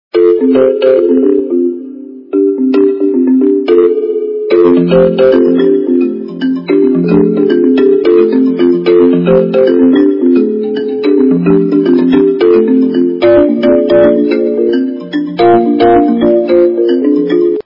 При прослушивании Звонок - Motorolla качество понижено и присутствуют гудки.
Звук Звонок - Motorolla